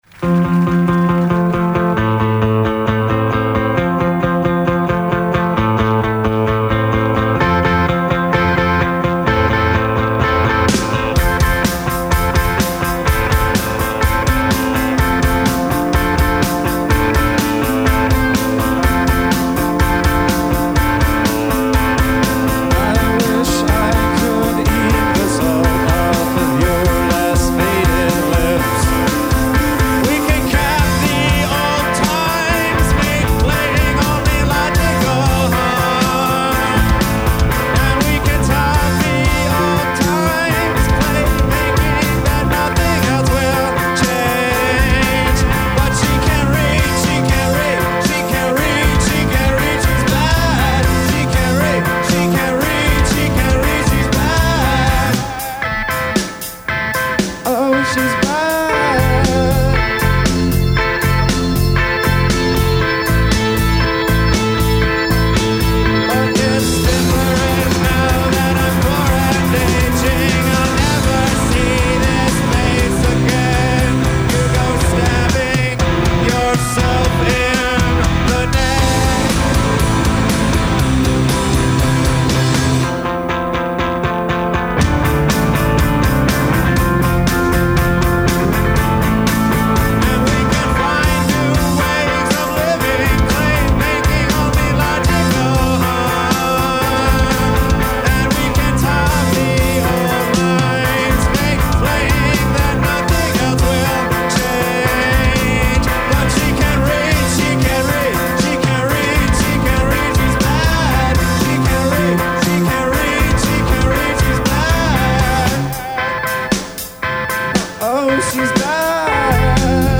enregistrée le 27/08/2002  au Studio 105